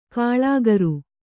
♪ kāḷāgaru